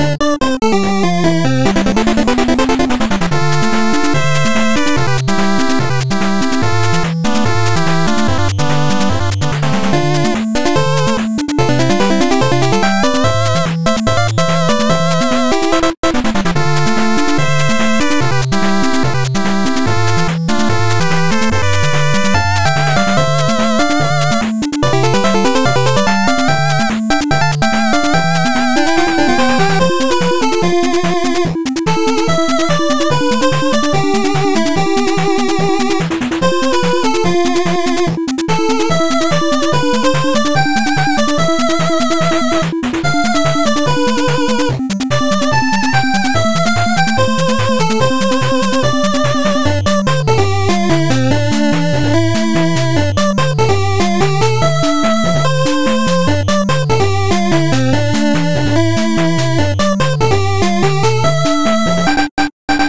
8bit music for boss battle theme.